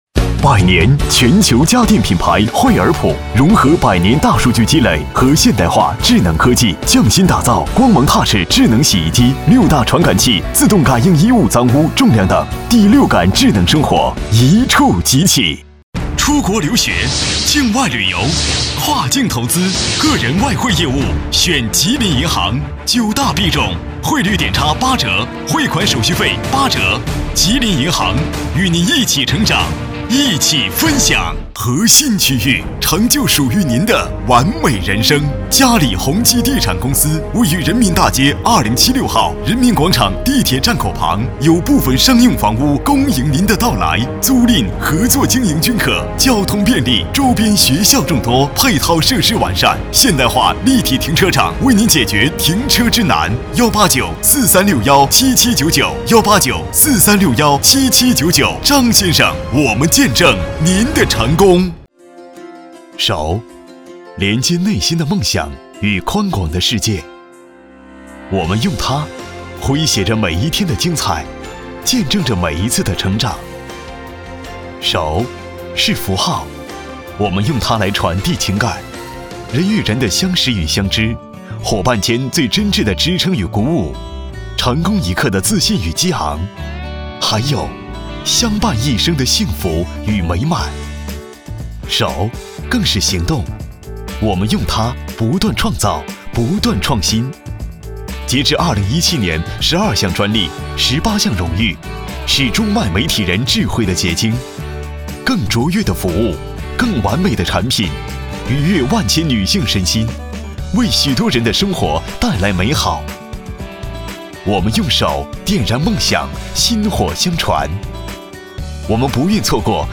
国语青年沉稳 、积极向上 、素人 、男课件PPT 、30元/分钟男9 国语 男声 自媒体 音乐类；林忆莲：忆莲幽梦，半生情愁(1) 沉稳|积极向上|素人